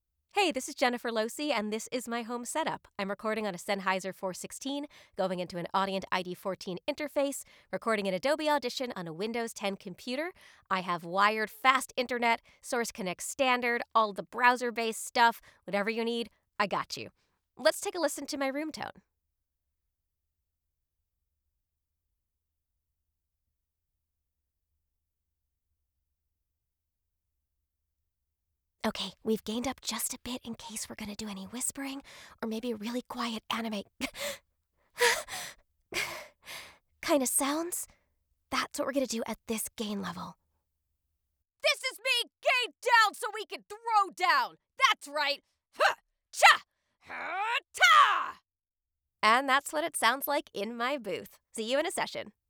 Recording Safely From Home
Here's a raw home studio sample.
• Mic: Sennheiser MKH 416 and Neumann U87
• Interface: Audient iD14